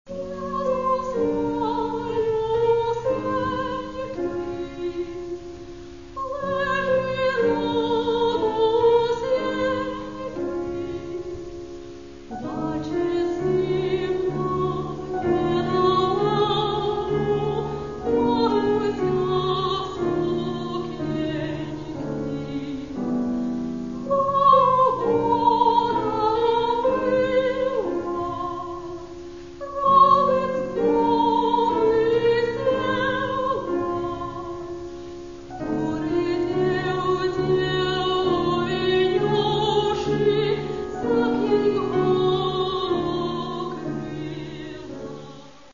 Каталог -> Класична -> Нео, модерн, авангард
для голосу і фортепіано